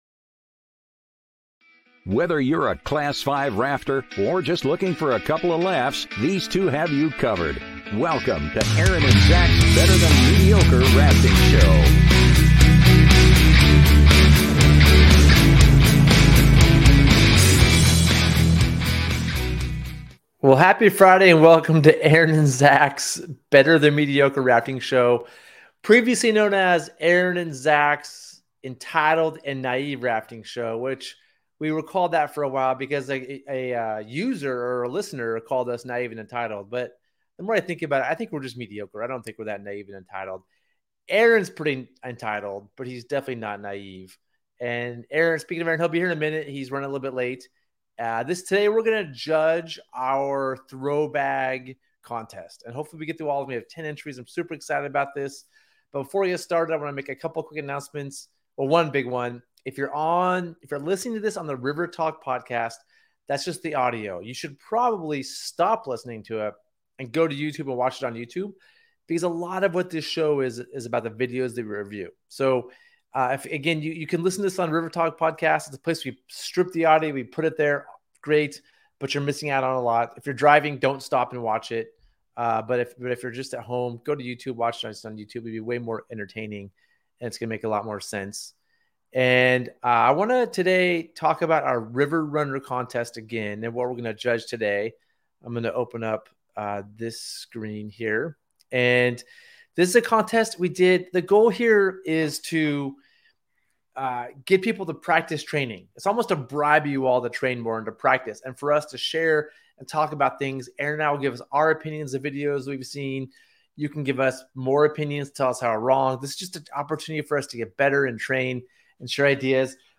Gear Garage Live Show